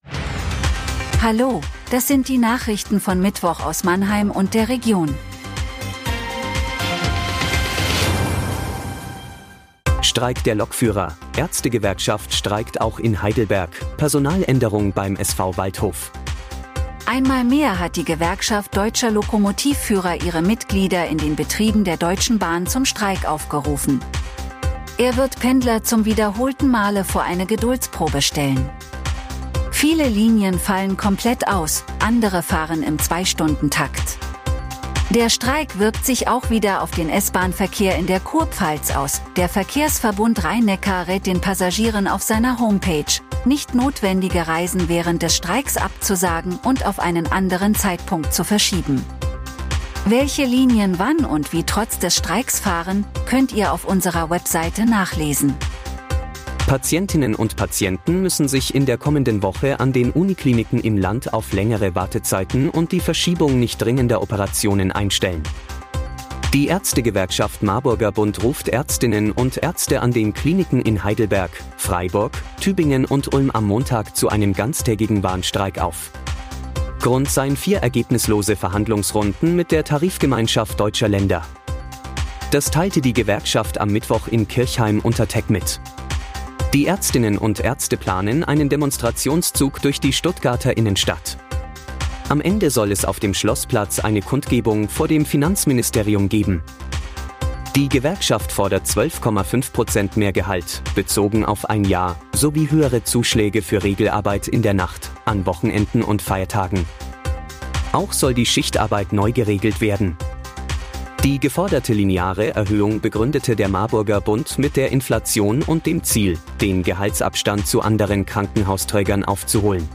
Der Nachrichten-Podcast des MANNHEIMER MORGEN
Nachrichten